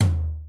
floor-tom.wav